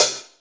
paiste hi hat2 close.wav